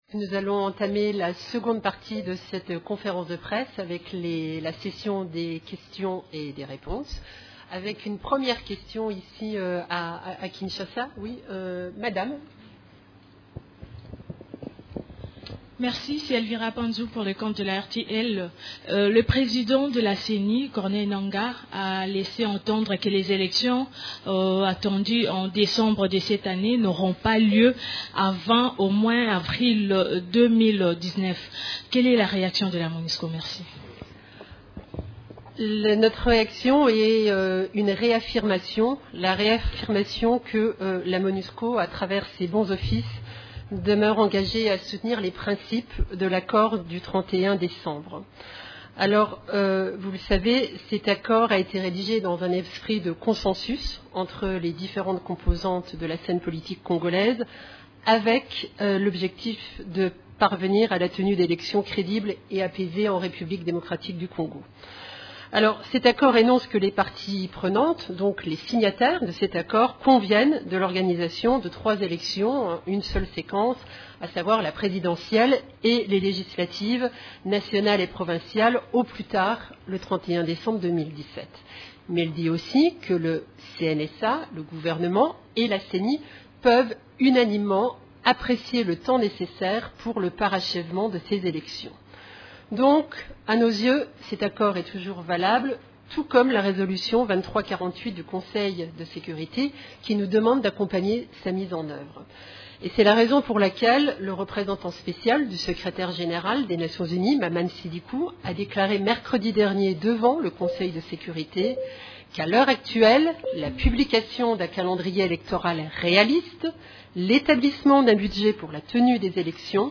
Conférence de presse du mrecredi 18 octobre 2017
La situation sur les activités des composantes de la MONUSCO, les activités de l’Equipe-pays ainsi que de la situation militaire à travers la RDC ont été au centre de la conférence de presse hebdomadaire des Nations unies du mercredi 20 septembre 2017 à Kinshasa: